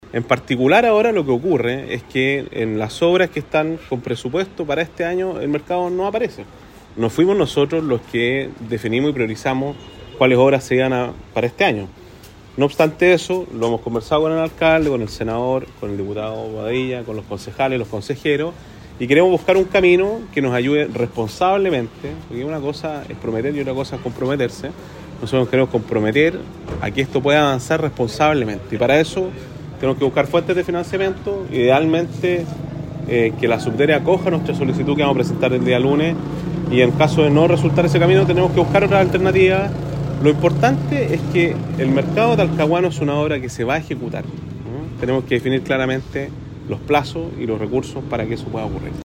“No fuimos nosotros los que definimos y priorizamos cuáles obras se iban a financiar para este año”, confesó el gobernador Giacaman sobre la no asignación presupuestaria para el Mercado Central.